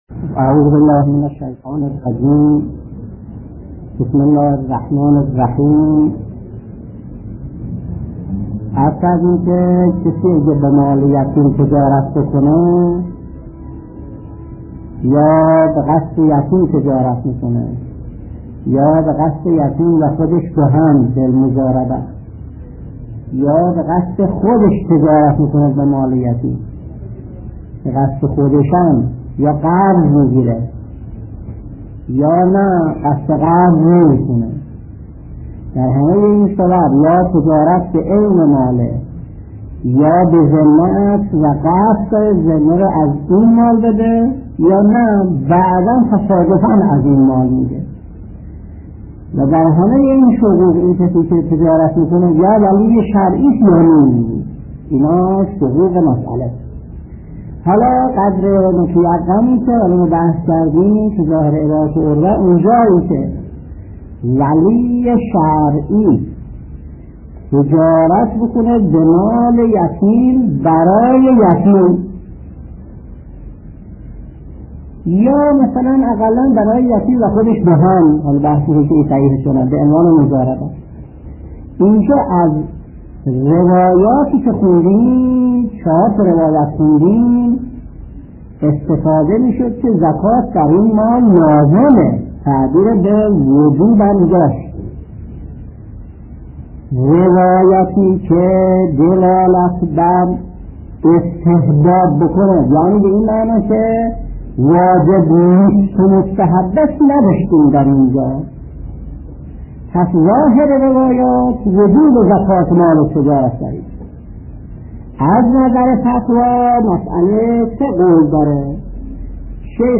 درس 38 : (31/1/1361) : سلسله درس های زکات